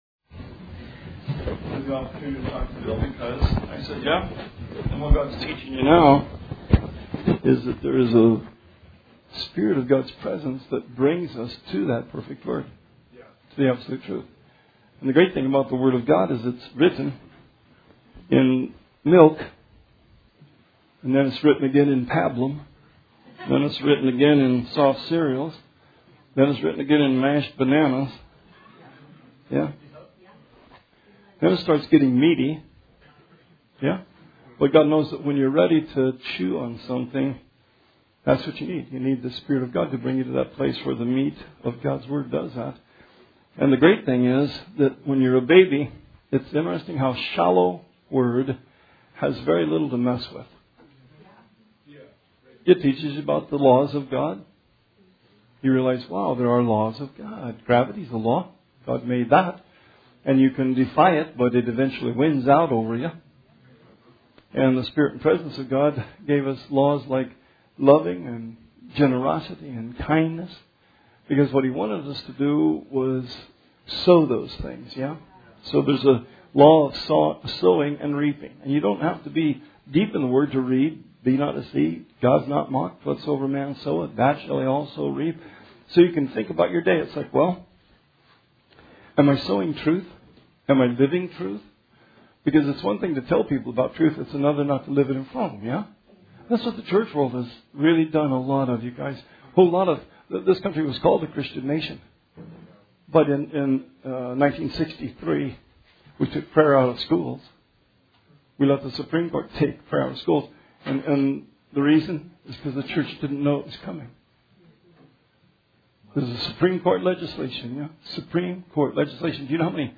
Sermon 12/15/19